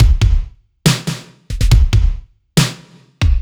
Index of /musicradar/french-house-chillout-samples/140bpm/Beats
FHC_BeatC_140-03_KickSnare.wav